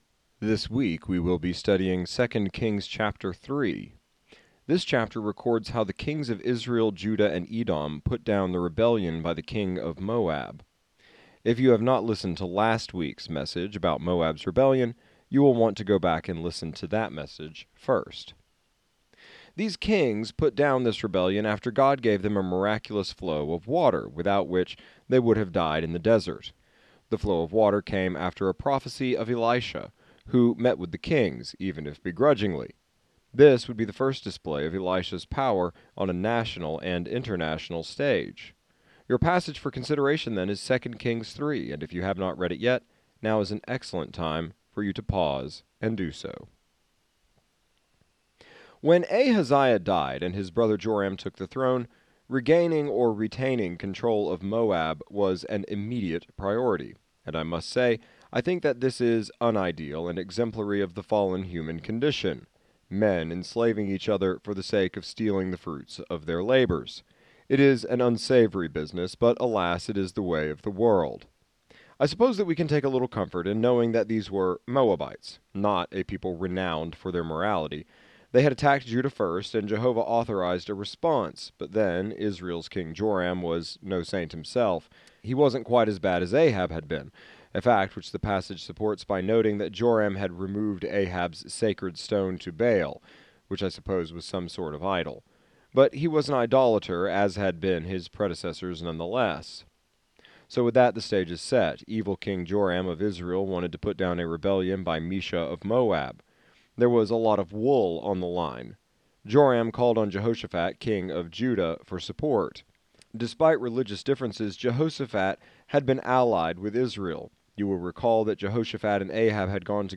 exegetical sermon series